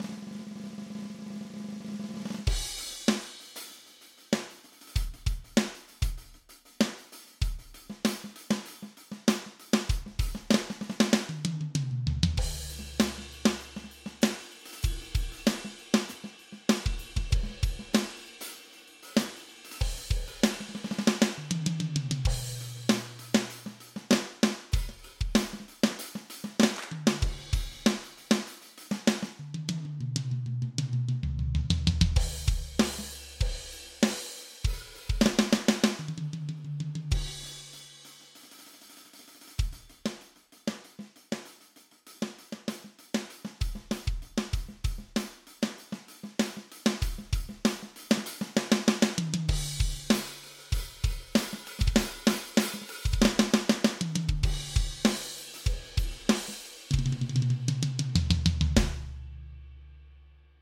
Hab versucht möglichst dynamisch zu spielen...
Wer es auch probieren will: Tempo ist 194 bpm. Hier mal einige Presets aus Addictive Drums 2 und am Ende mein selbstgebasteltes Preset, das ich gerne für härteren Rock nehme.